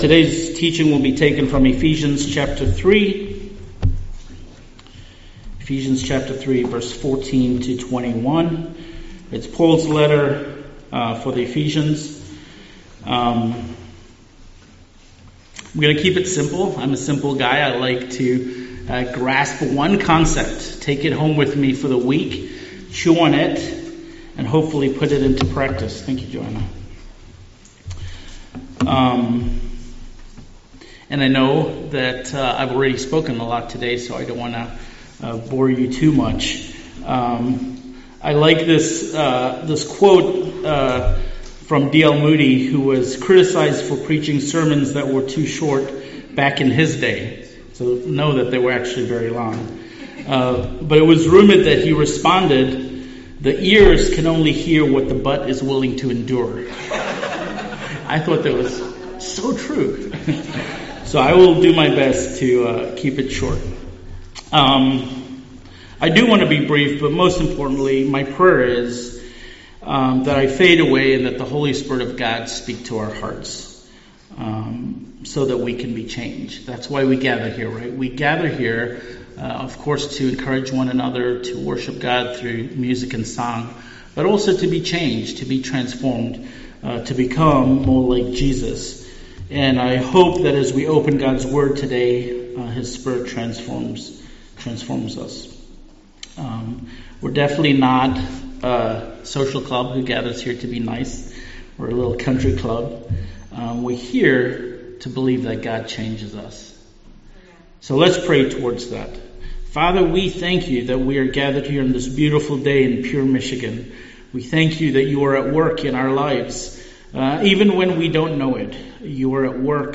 CMC Sermon